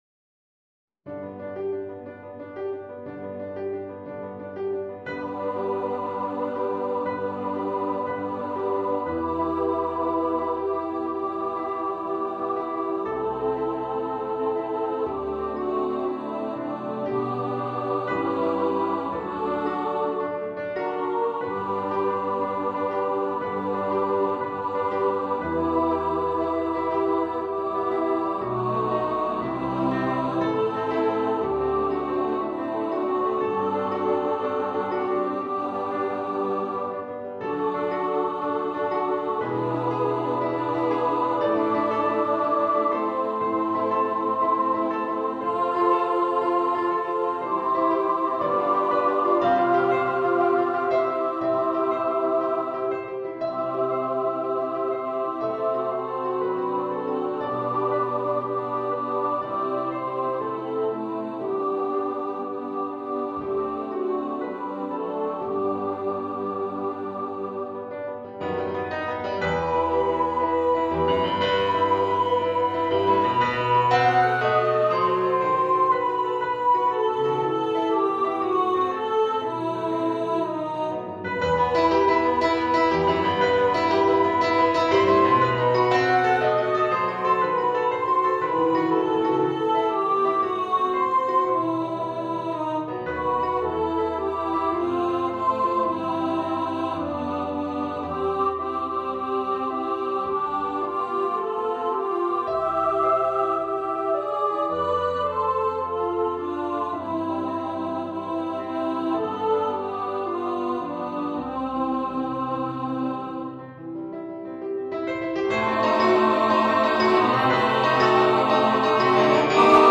A carol for Christmas
for choir with orchestra or piano
Choir (SATB or SA+Men or SSA or TTBB)
(Choir - 3 part upper voices)